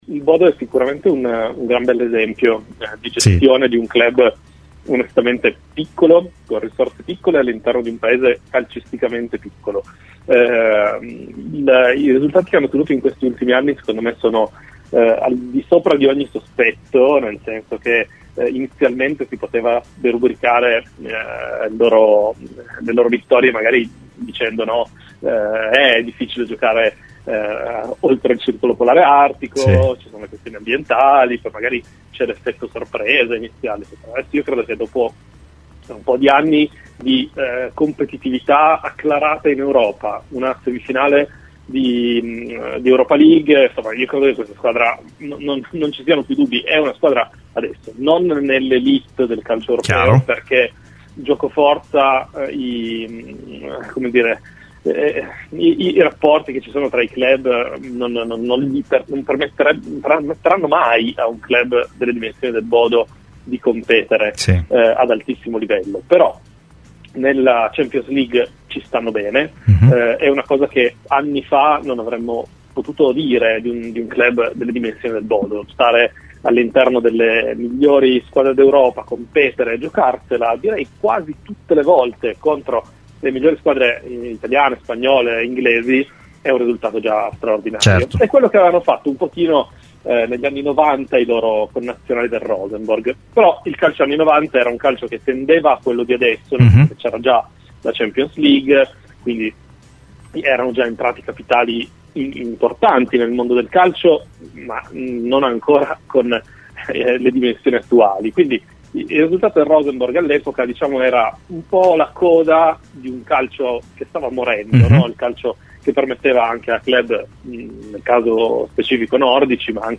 Ospite de "L'Ora della Vecchia Signora" su Radio Bianconera